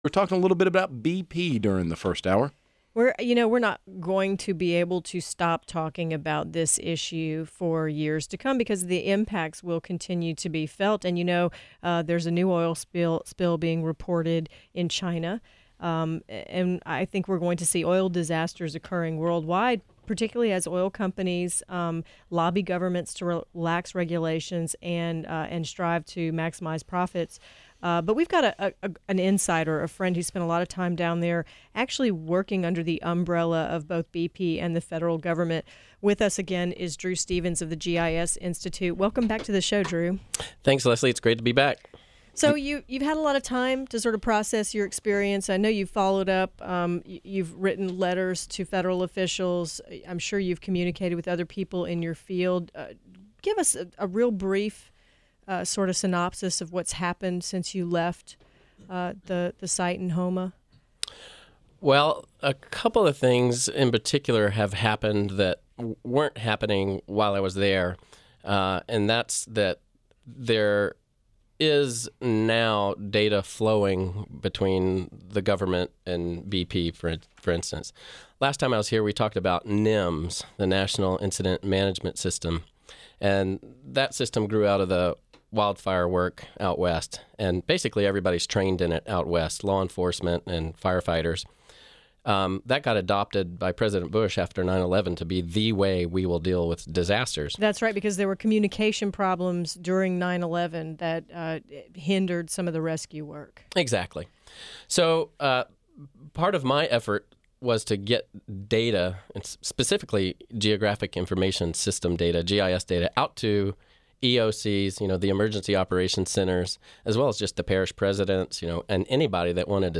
BP talk on the Revolution